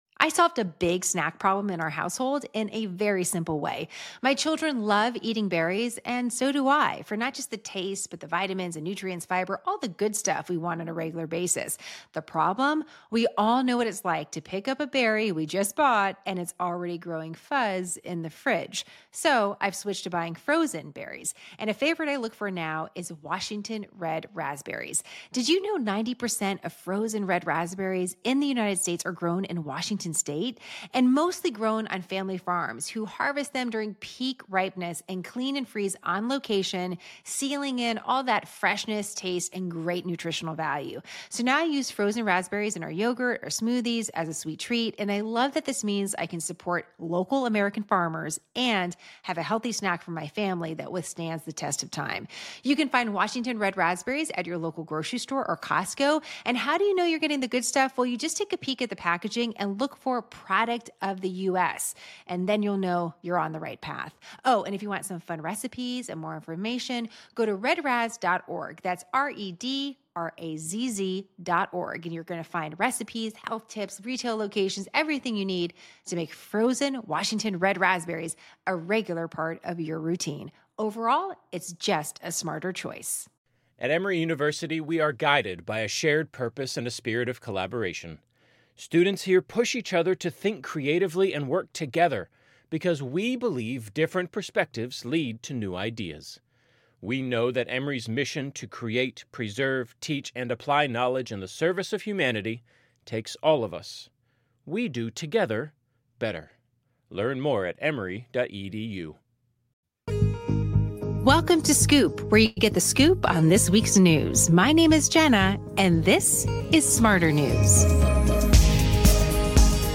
✓ 22:00: We hear from Israeli Prime Minister Benjamin Netanyahu during his speech at last week’s United Nations General Assembly (UNGA). ✓ 26:00: We hear from the new Iranian president Masoud Pezeshkian during his speech at UNGA. ✓ 30:00: Lastly, we hear from Ret. Lt. Gen. HR McMaster, a former White House National Security Advisor.